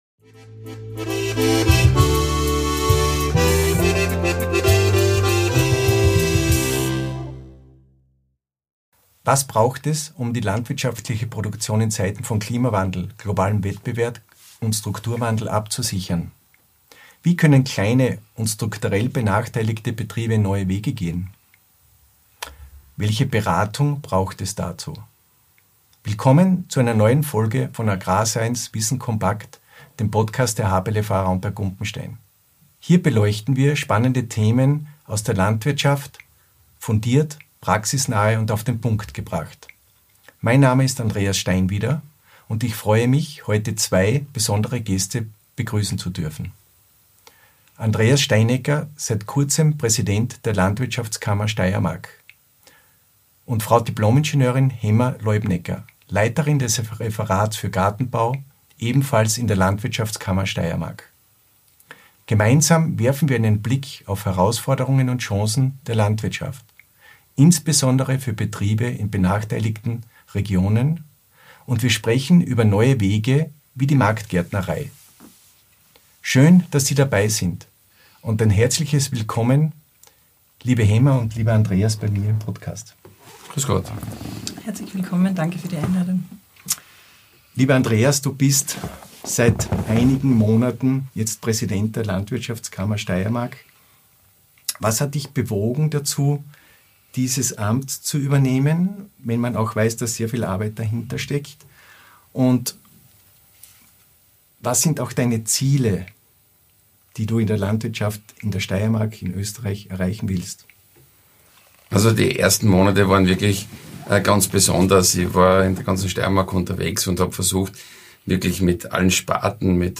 Gemeinsam diskutieren wir, wie innovative Ansätze helfen können, Landwirtschaft resilient und zukunftsfähig zu gestalten.